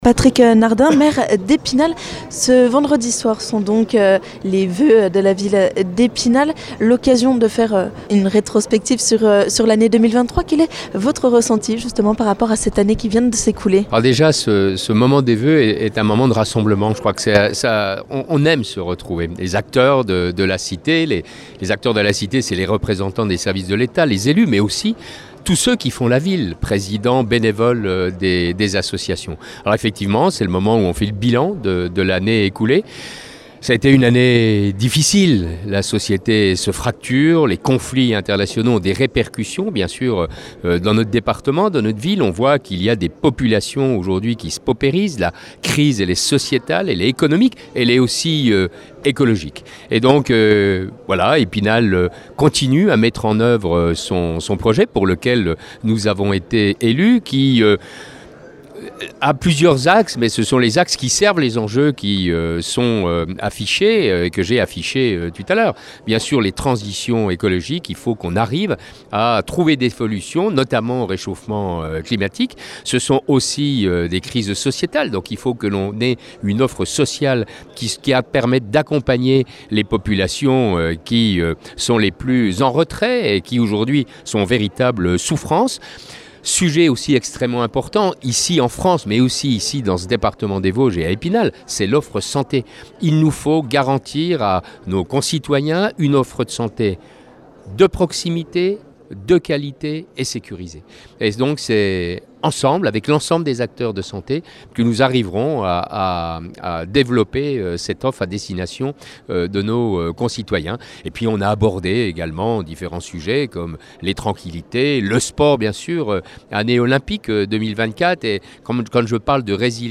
Vendredi soir, Patrick Nardin, maire de la Ville d'Epinal, a tenu sa cérémonie de voeux. Il a notamment fait un point sur l'offre de santé sur son territoire.